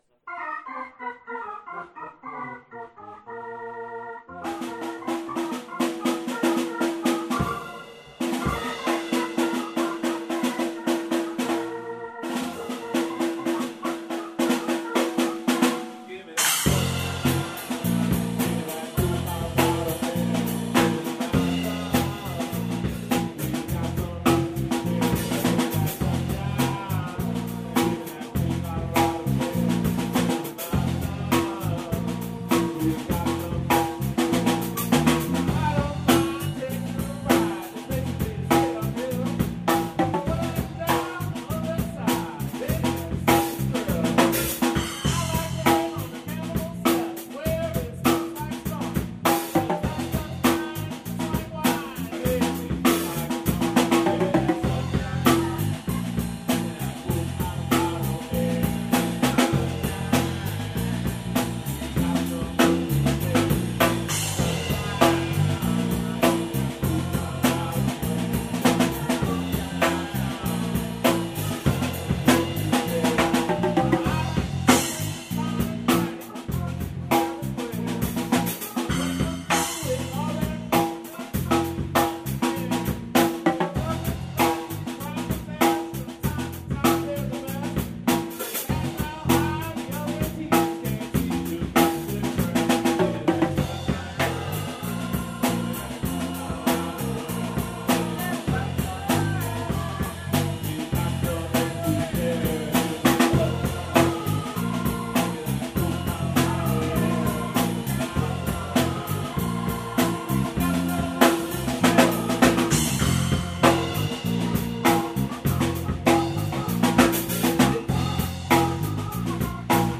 Rehearsal at Denver Drums